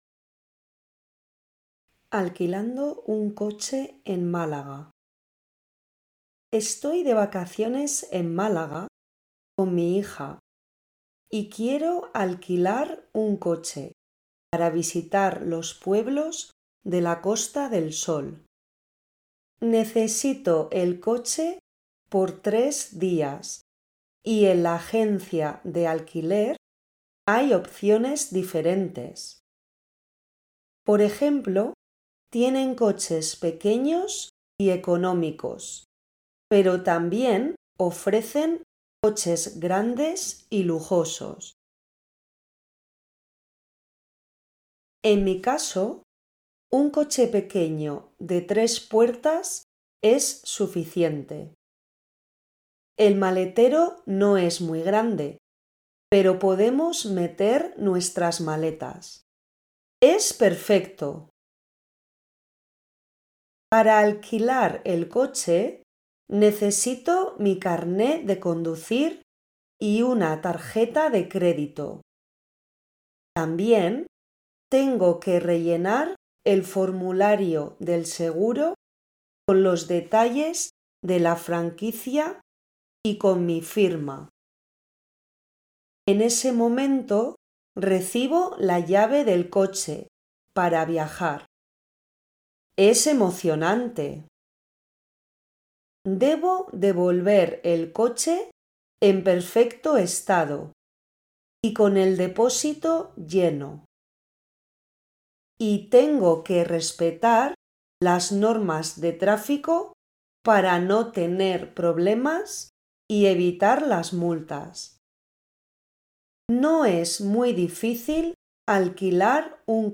Spanish online reading and listening practice – level A1